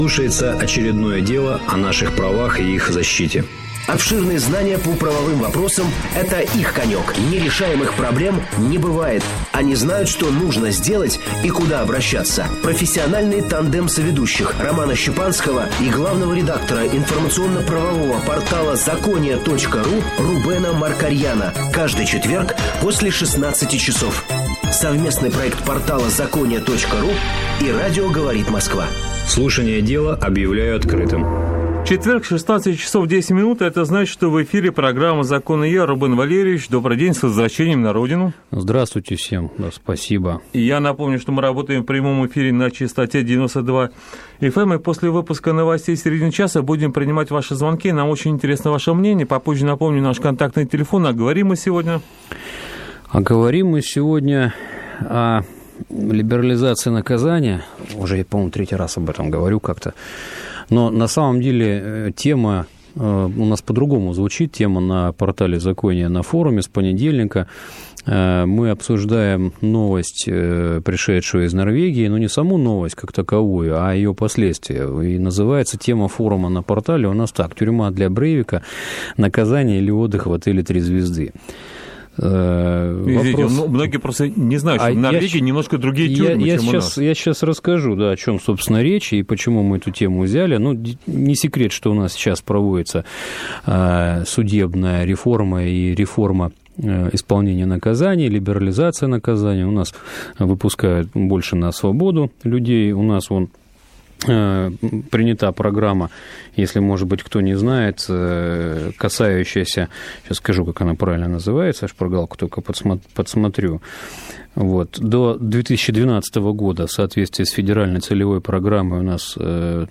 СЛУШАТЬ ЭФИР (04.08.2011)